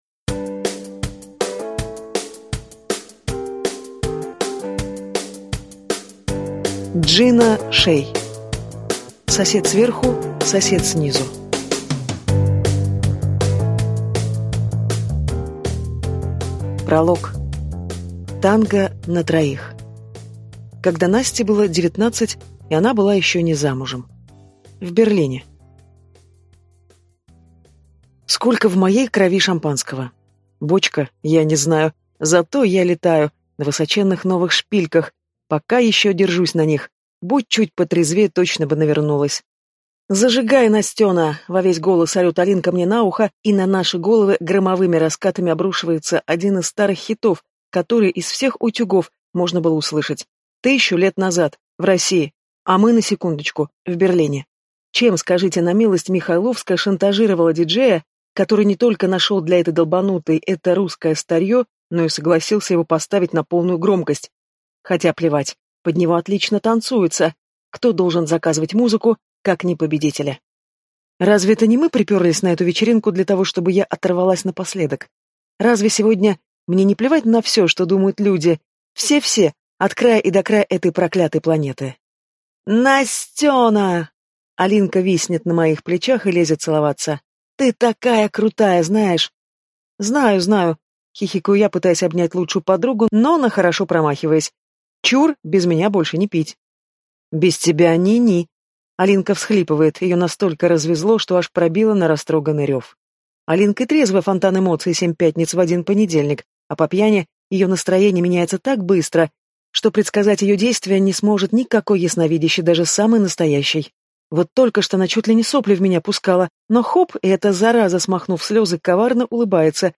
Аудиокнига Сосед сверху, сосед снизу | Библиотека аудиокниг